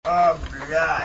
minigame_bomb.mp3